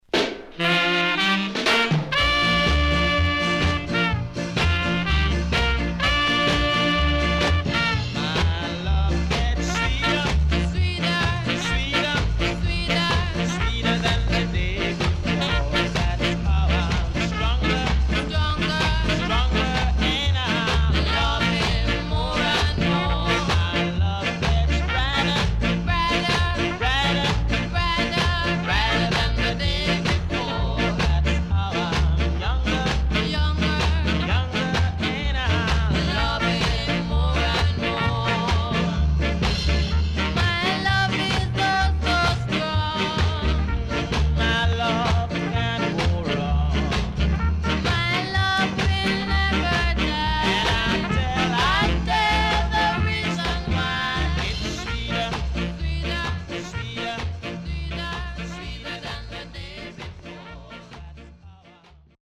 HOME > REISSUE [SKA / ROCKSTEADY]